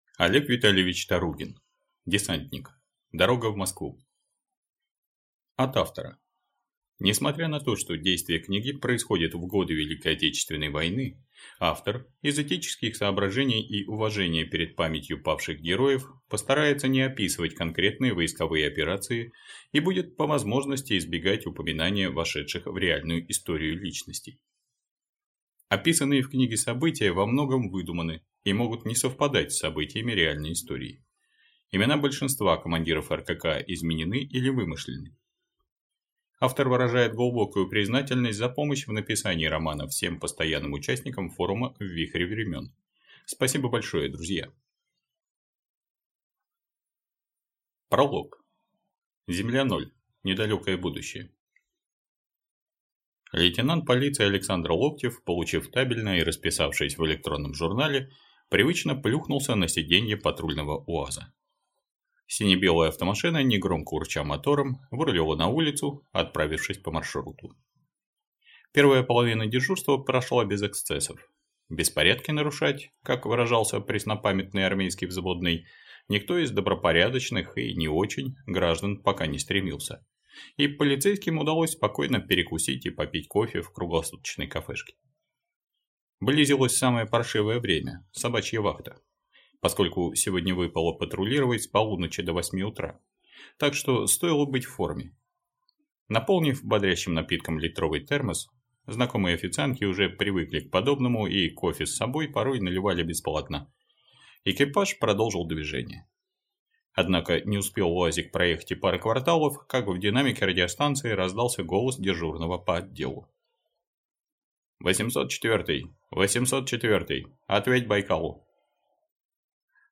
Аудиокнига Десантник. Дорога в Москву | Библиотека аудиокниг
Прослушать и бесплатно скачать фрагмент аудиокниги